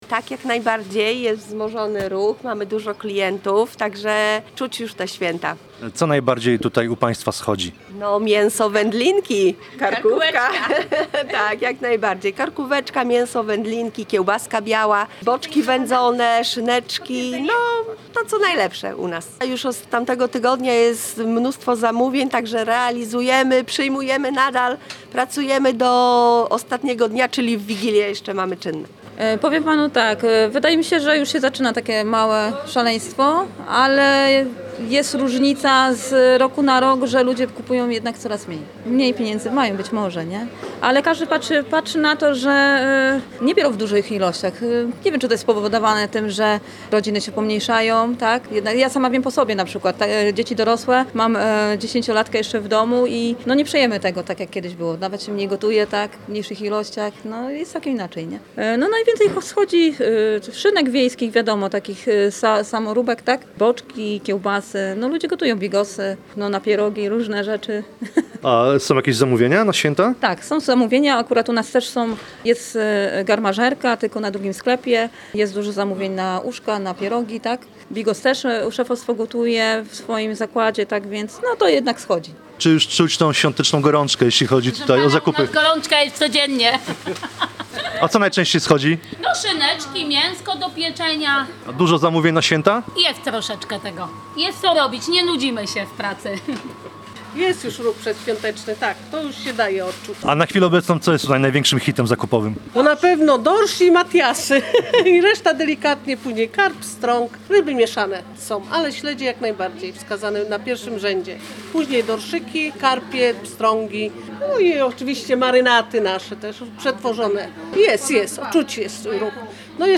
Odwiedziliśmy lokalne targowisko, by sprawdzić, jak wyglądają ostatnie dni przed świętami z perspektywy sprzedawców i klientów. Jakie produkty na stół świąteczny cieszą się największym zainteresowaniem?
Posłuchaj materiału naszego reportera: https